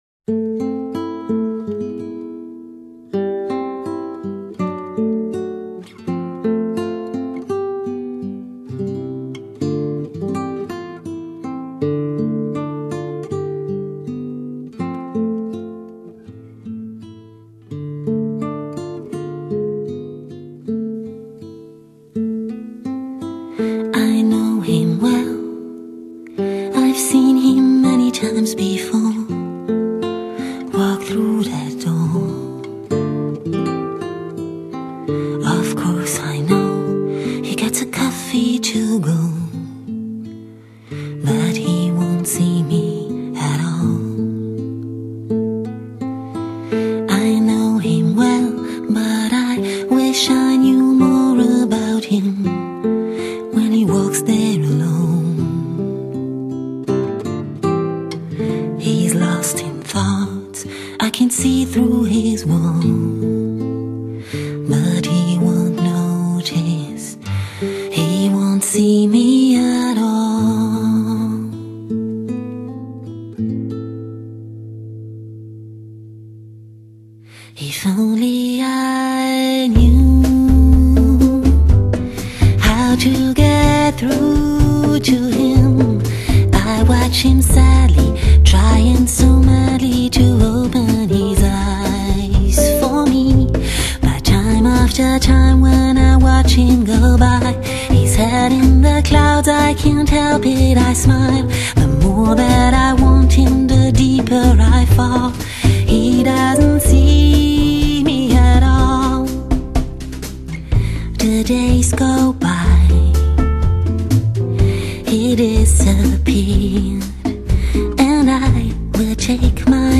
清新的乐风、恣意荡漾的随兴，干净、温暖、又保留一丝距离感的音色让你相信 --- 其实简单的音乐反而是更深刻的。
干净的声音很适合疲惫了一天的神经，听着木吉他缓缓地弹揍出的音符，闭上眼睛，好好享受难得的安静。